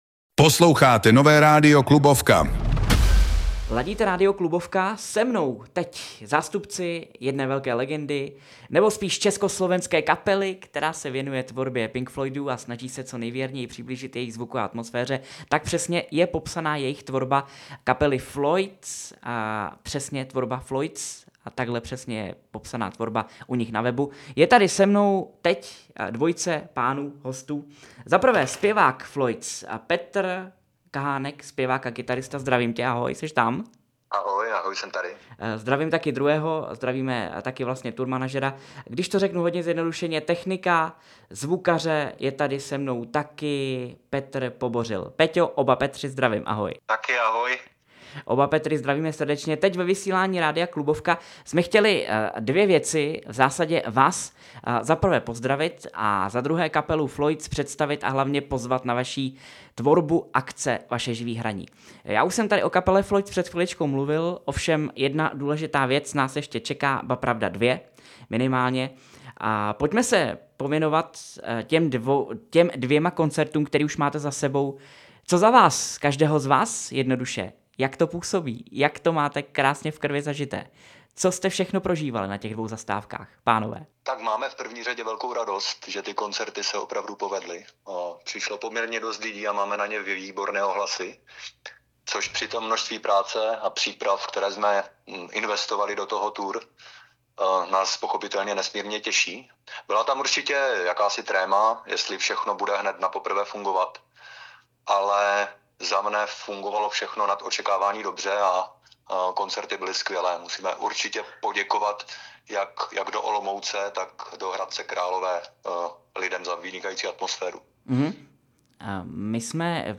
floyds-rozhovor-komplet.mp3